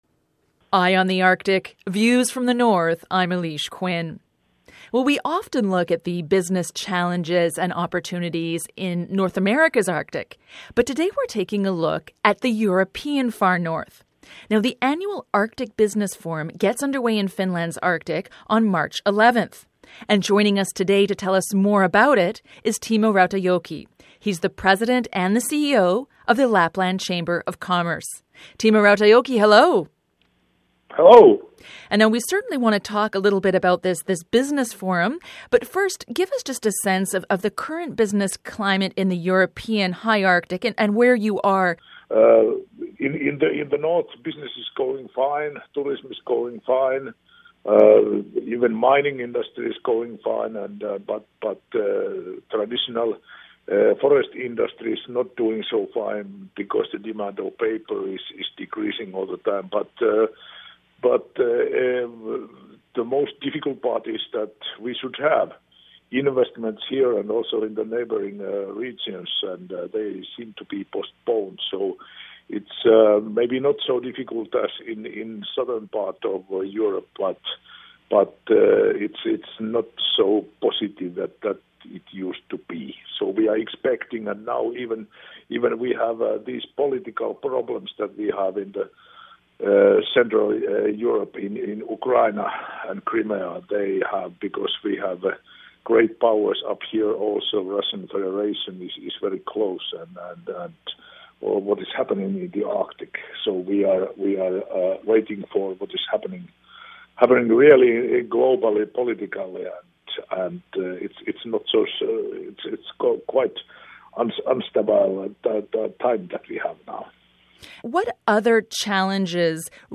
Feature Interview: The need for business cooperation in the Arctic